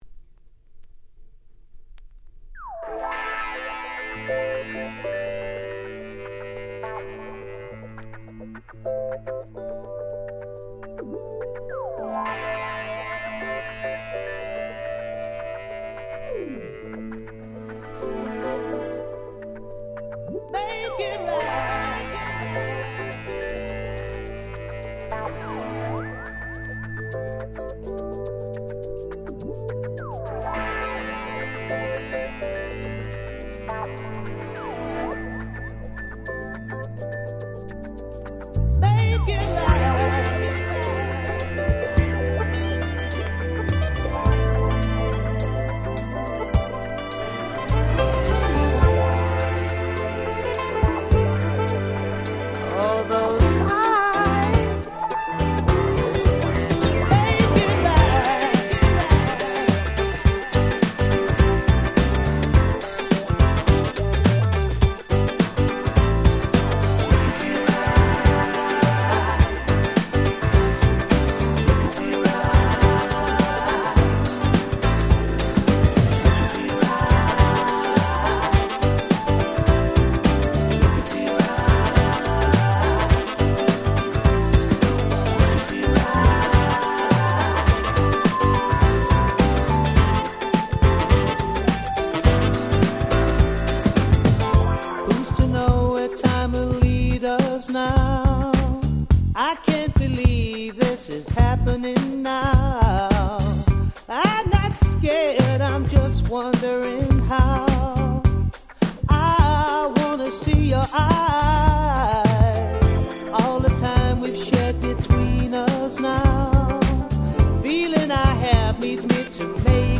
SmoothでGroovyなMidダンサー！